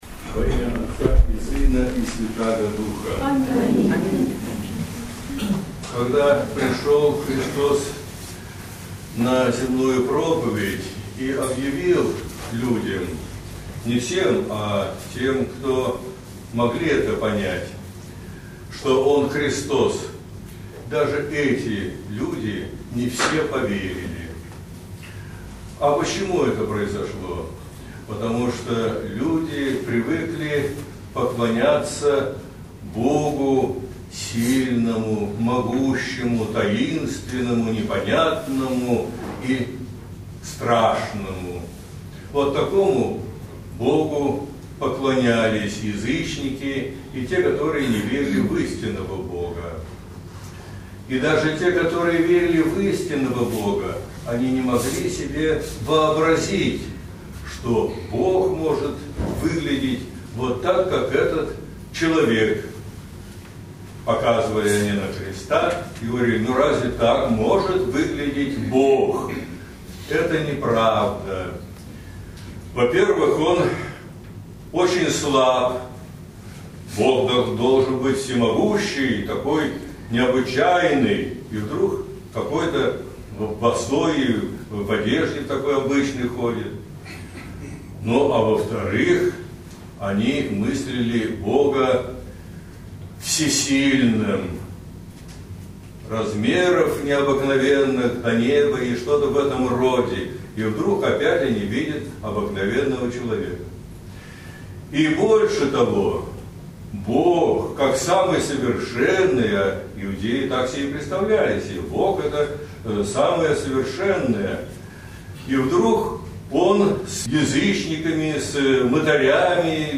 Проповеди (аудио) Бог и как мы Его себе представляем 04.01.2012 Послушать